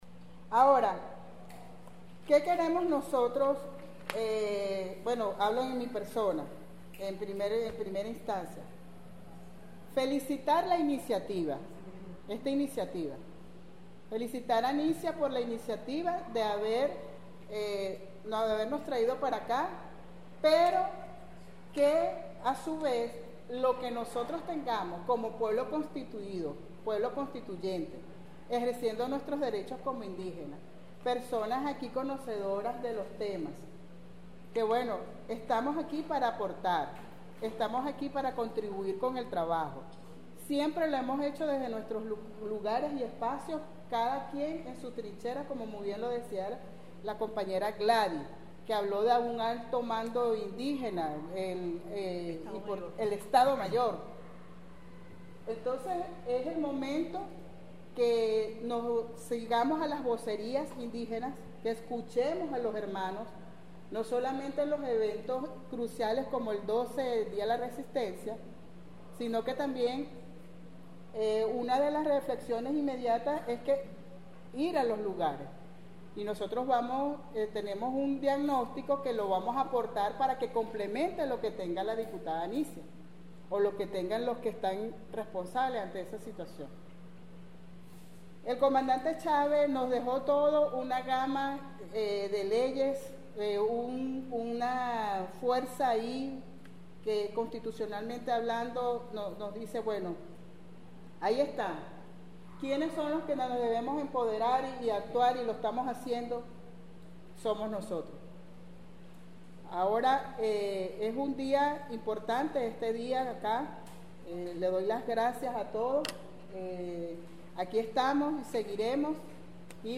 Exitoso encuentro de lideresas y líderes indígenas con sus Legisladores Nacionales